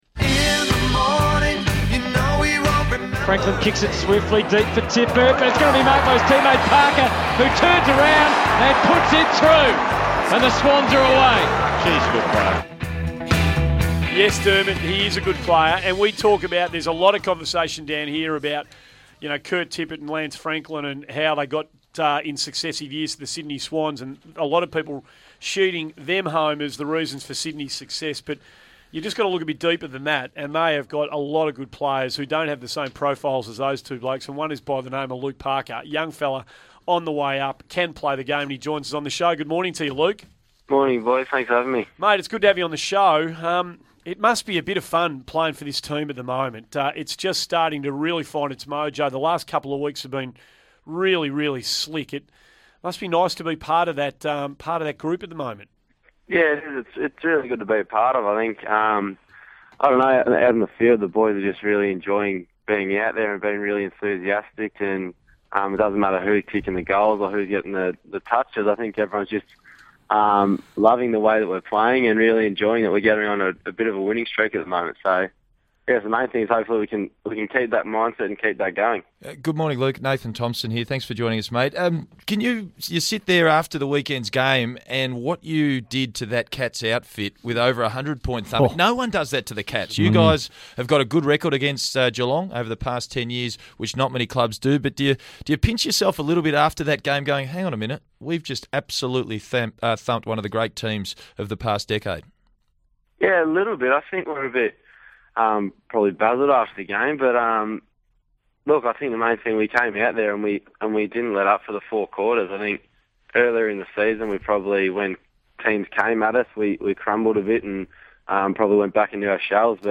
Sydney Swans midfielder Luke Parker appeared on 1116SEN's Morning Glory program on Tuesday June 3, 2014